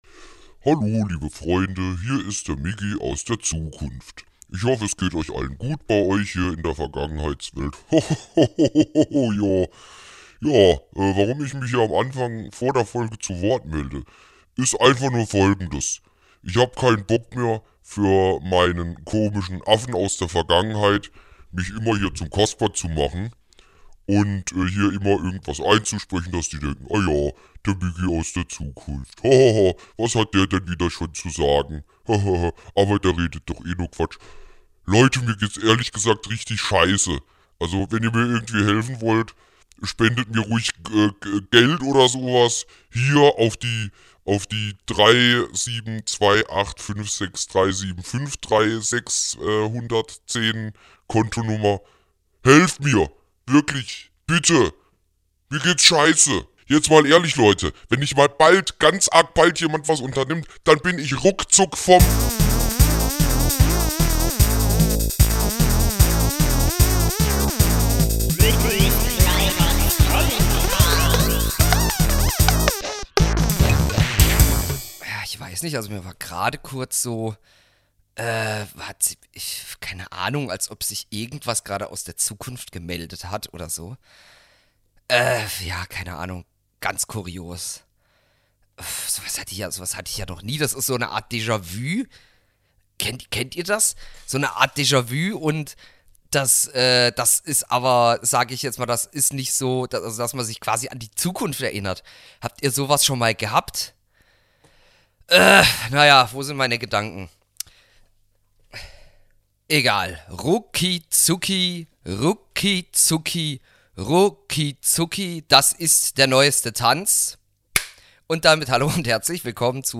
Folge 23: Rumtitelei (Essay